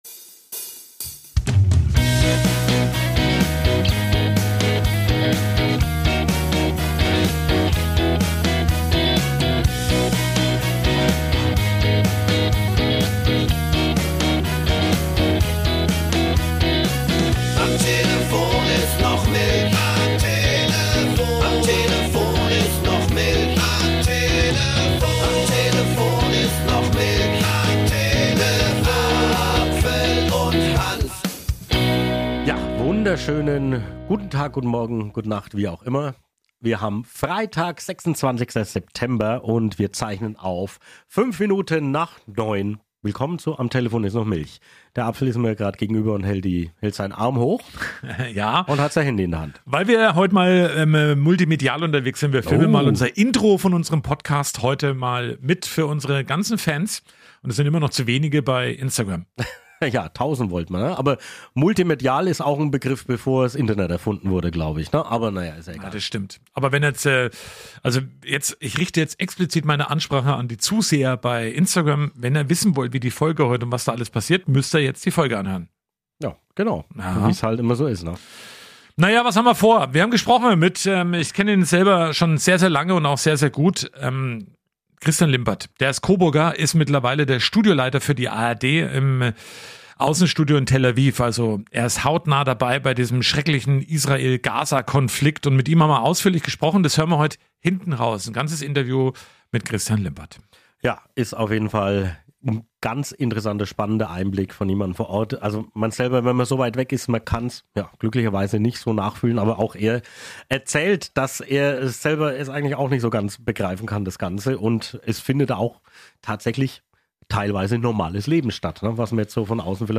Der Wochenrückblick
Dazu gibt es viele Berichte und Interviews
Küpser Dialekt